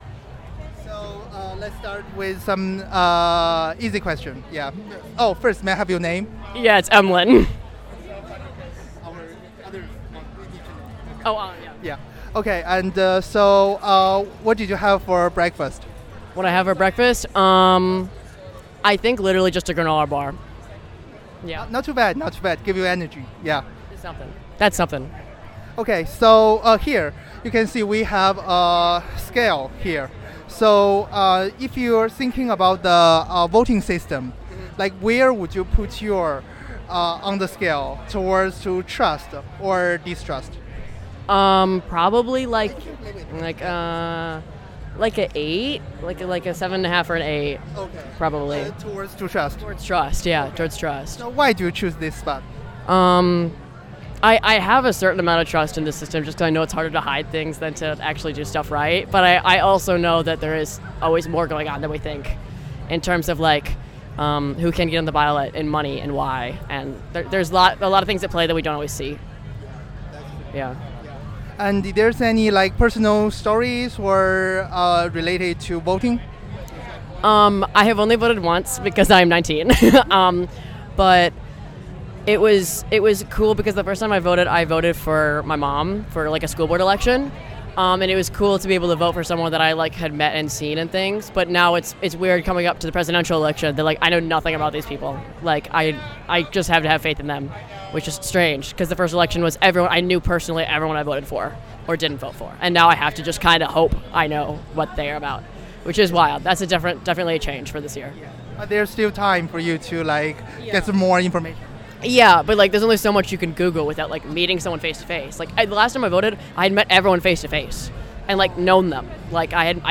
Location MKE Pridefest